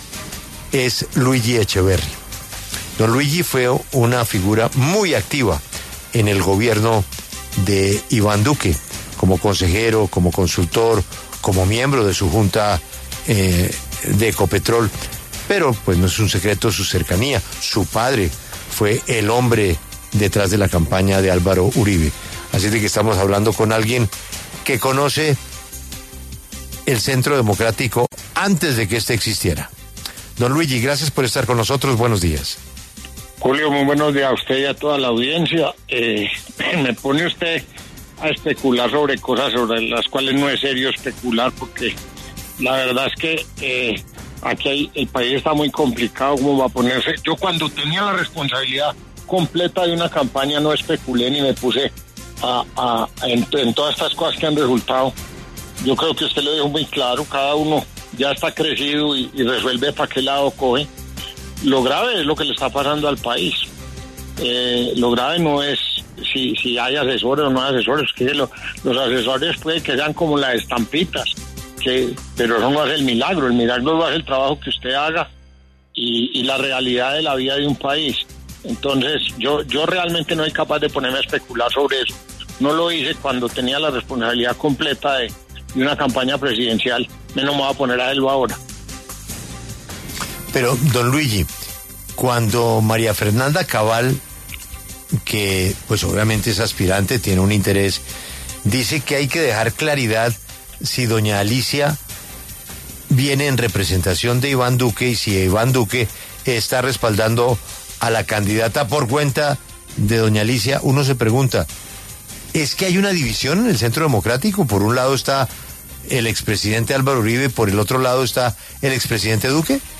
conversó con La W sobre el panorama electoral de cara al 2026 y la alianza de Vicky Dávila con Alicia Arango.